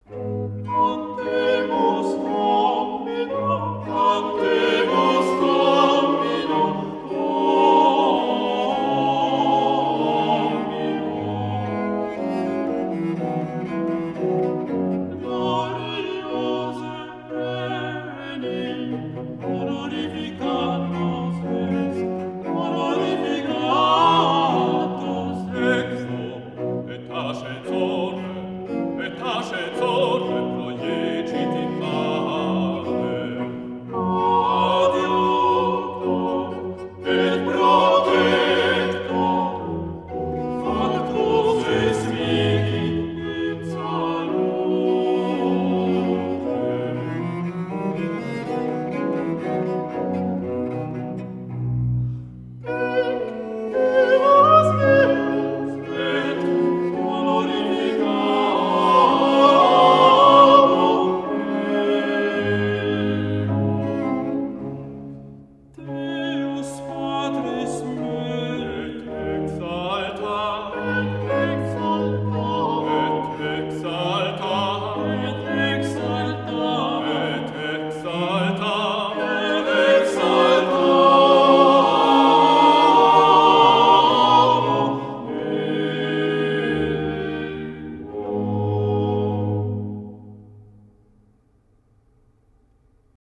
Ensemble Turicum.